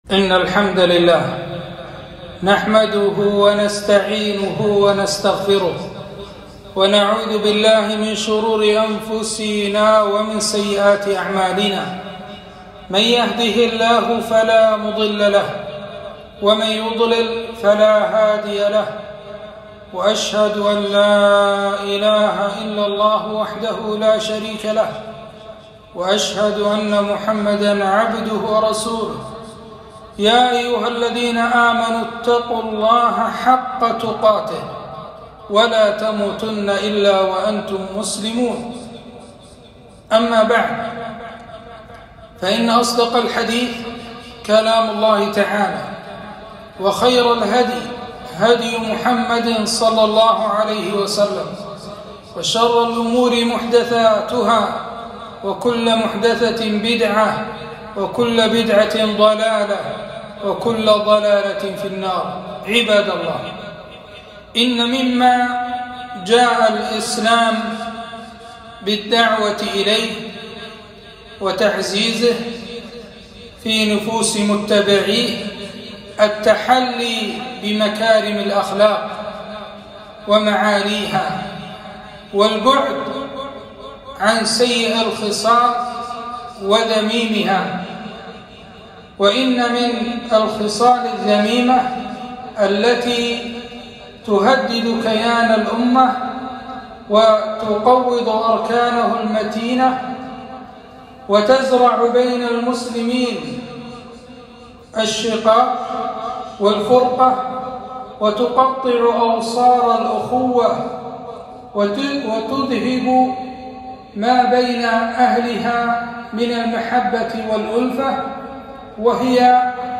خطبة - النهي عن السخرية من خلق الله